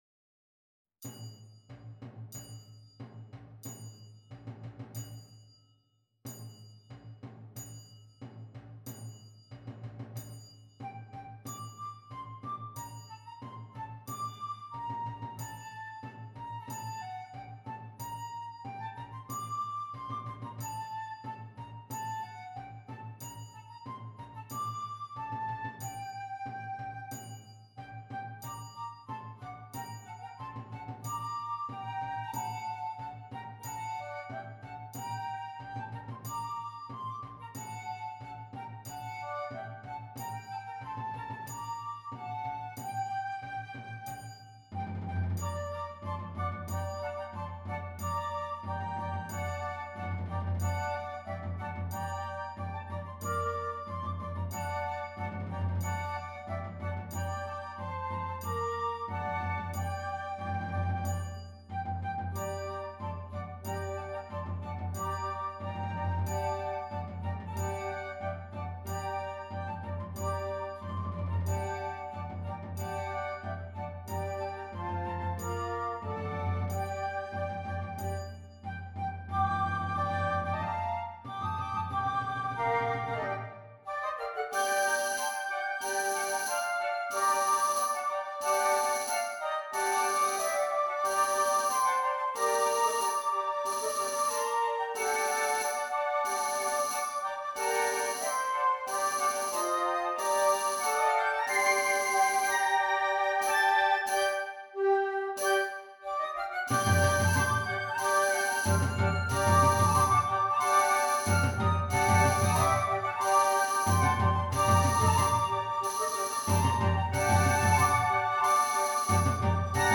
6 Flutes
Traditional French Carol